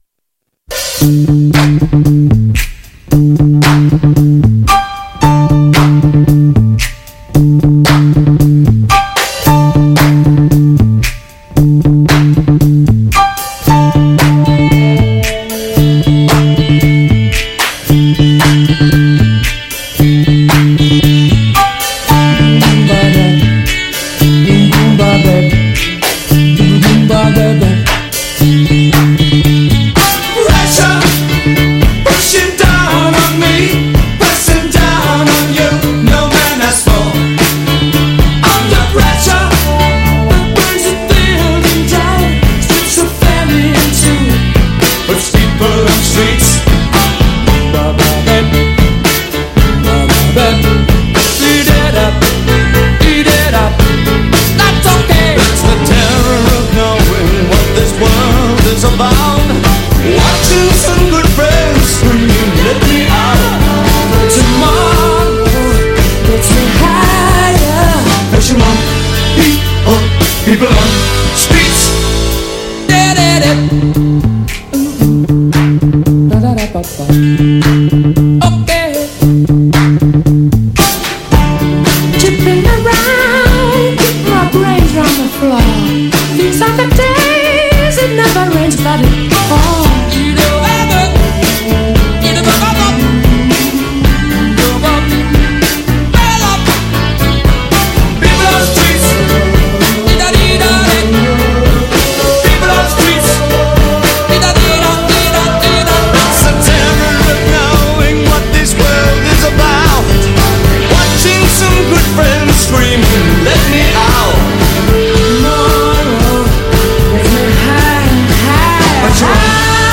Intervista per “Queen at the opera” | 28-11-22 | Radio Città Aperta